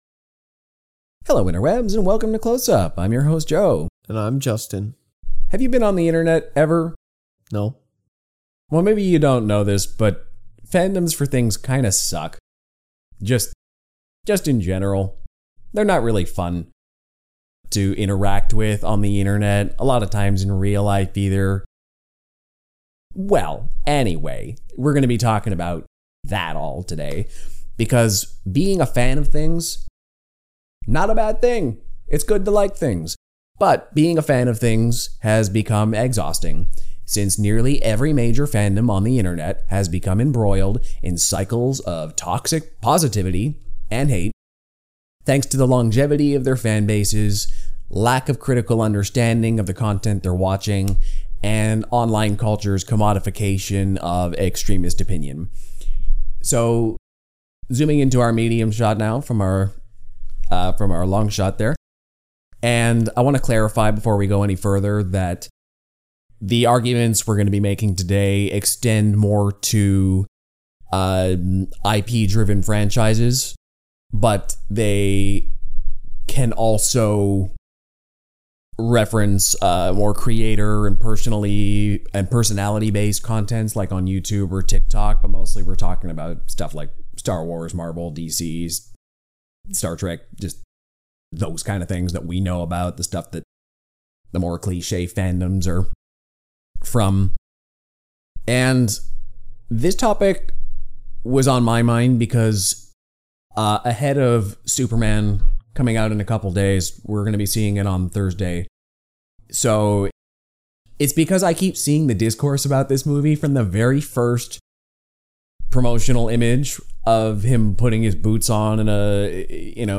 recorded live from Gotham City limit comic shop. We answer some fan mail questions, and talk about the comics, creators, and trends that made this a great year for comic fans, and the things we're excited about for 2026.